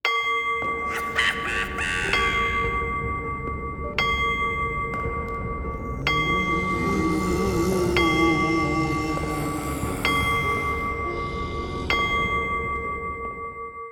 cuckoo-clock-07.wav